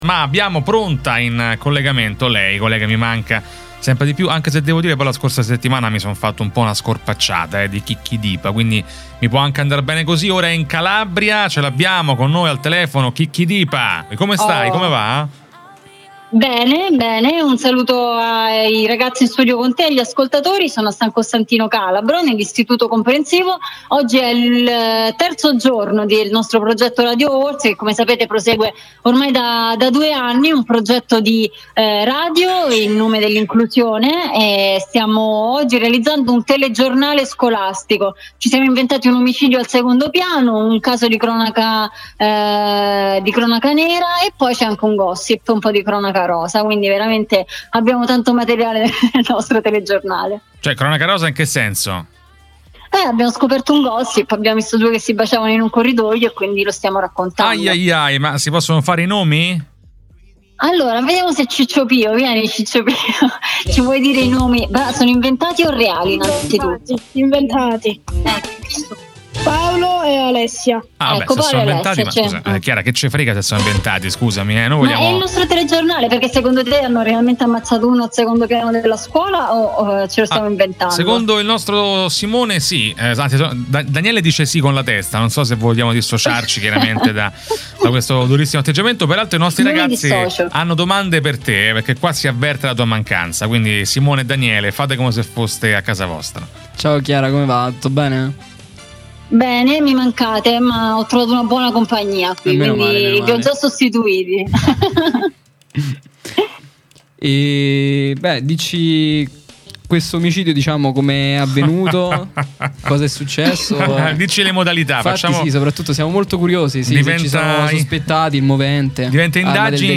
La nostra giornalista e speaker radiofonica in collegamento da San Costantino Calabro dove prosegue il progetto di Radio Hogwartz